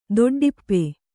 ♪ doḍḍippe